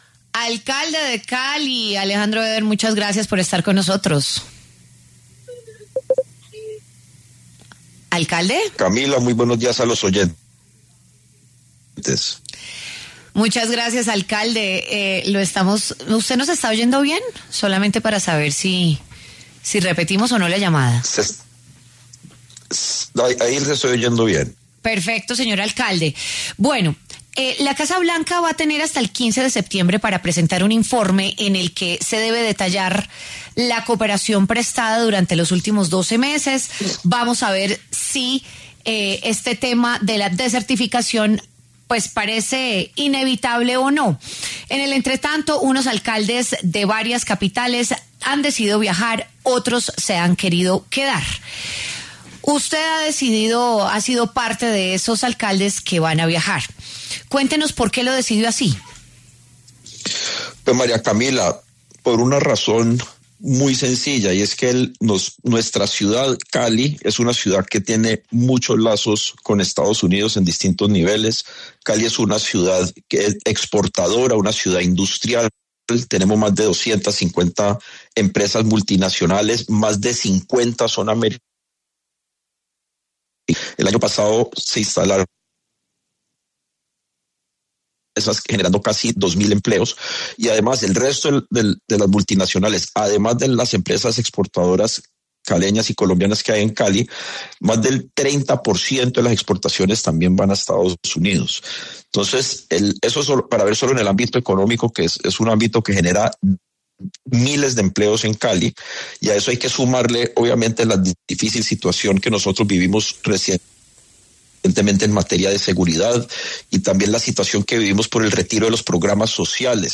Alejandro Éder, alcalde de Cali, habló en W Fin de Semana del choque contra el presidente Gustavo Petro por el viaje que preparan los mandatarios locales a Washington en medio del riesgo de descertificación de Colombia en la lucha contra el narcotráfico.